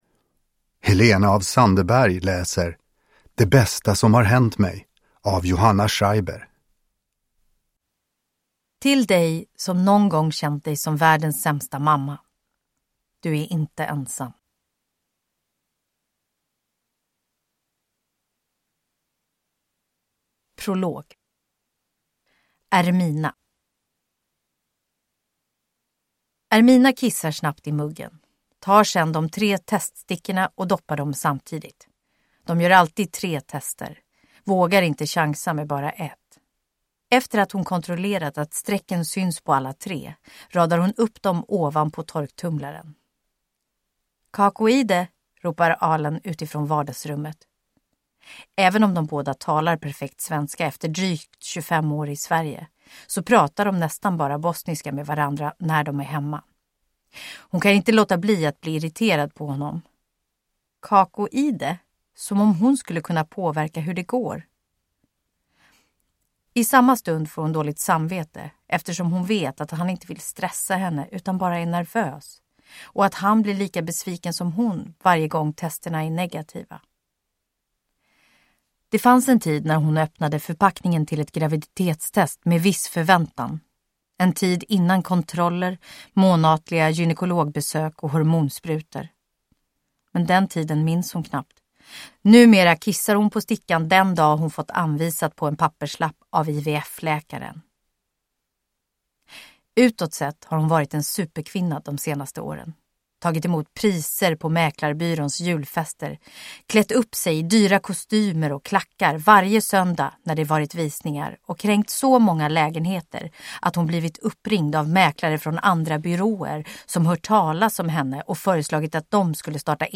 Uppläsare: Helena af Sandeberg
Ljudbok